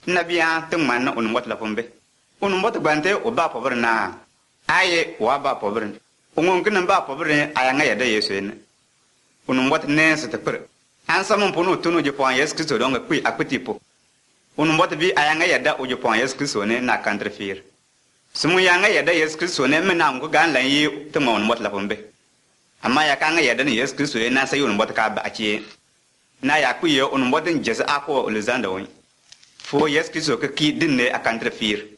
It has the typical “downdrift” intonation of languages from that region, where the phonemic tones in successive words in the same phrase “drift” downward toward the end of a phrase; in other words, their absolute tonal pitch is lower the farther you get from the beginning of the phrase they are in. I hear the neutral central vowel [ə] all over the place, and a lot of consonant clusters that are not typical in most Niger-Congo languages, so I’m guessing this is either a Kordofanian language or a Nilo-Saharan language.